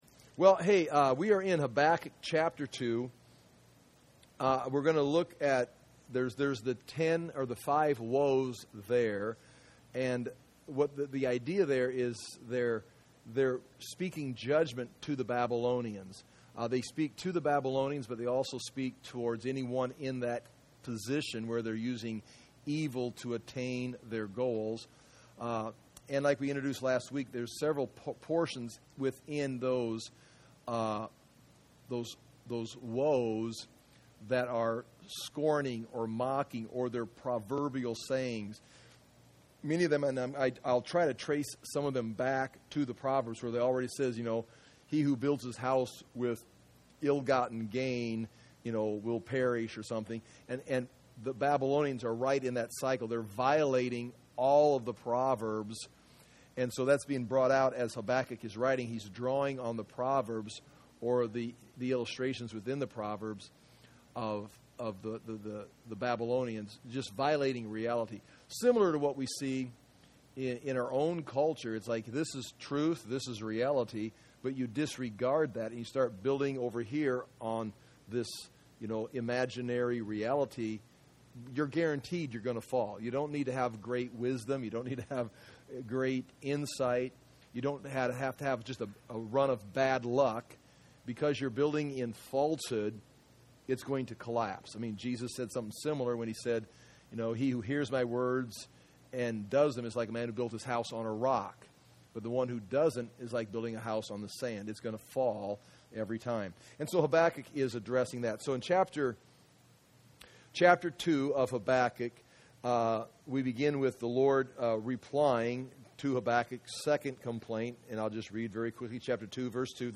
Habakkuk - verse by verse Bible teaching audio .mp3, video, notes, maps, lessons for the Book of Habakkuk